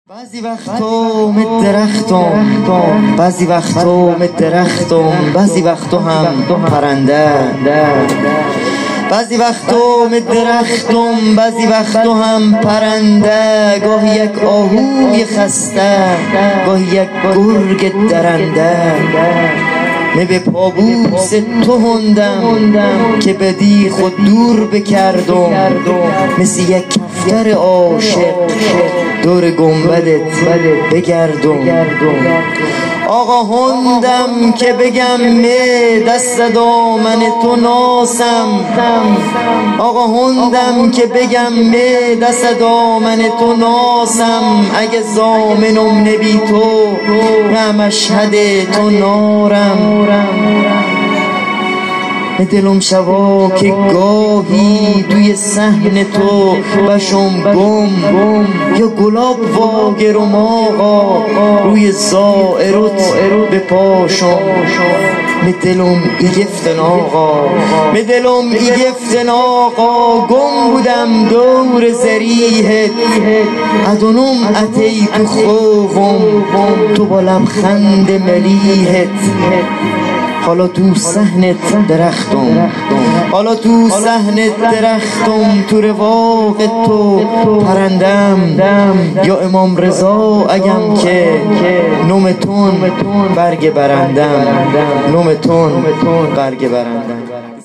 سالگرد شهید سردار حاج قاسم سلیمانی سه شنبه ۱۱دی۱۴۰۳